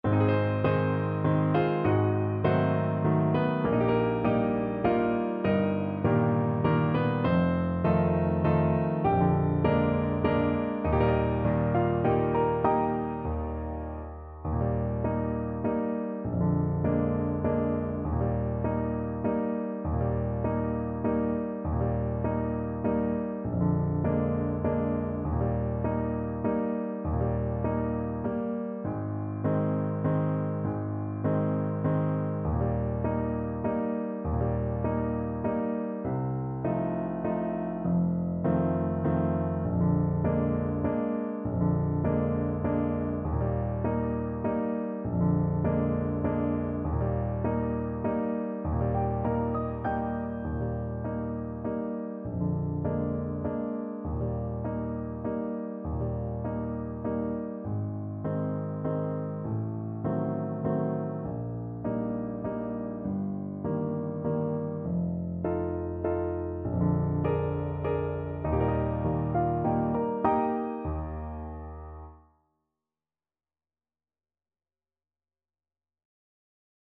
3/4 (View more 3/4 Music)
~ = 100 Slowly and dreamily
Classical (View more Classical Saxophone Music)